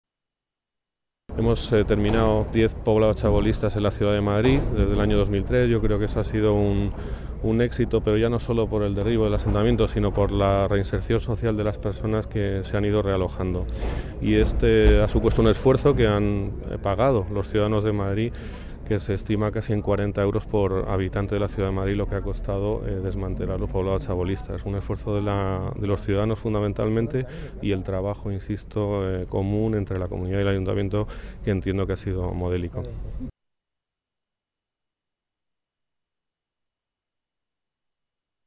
Nueva ventana:Declaraciones de Juan José de Gracia, coordinador general de Gestión Urbanística